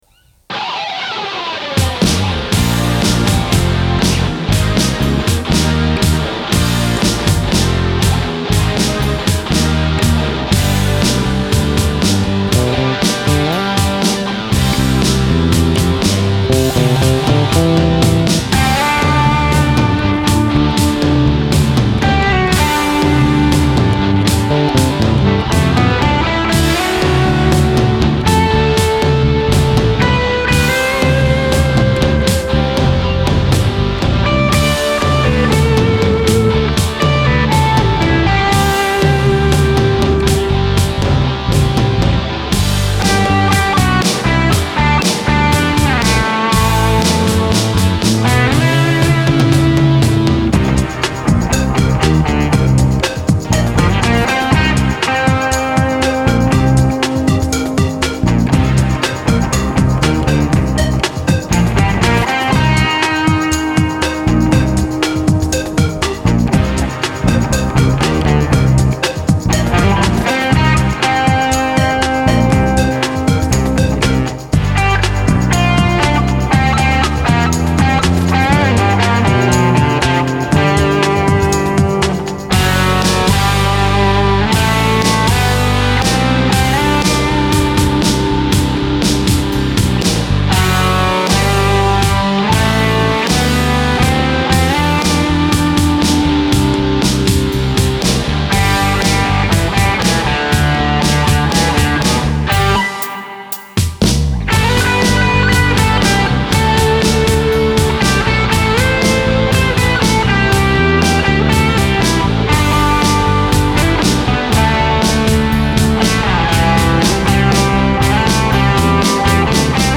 Einmal mit dem Reson Rack, bestückt mit dem YOKO Bandsplitter und dreimal den Mastering Bus Compressor; und einmal mit dem Cubase Multiband Compressor und dem Reason Rack Plugin nur mit dem Maximizer.
Cubase Multiband-Compressor:
Hanging_Rock_9_Cubase_Multiband.mp3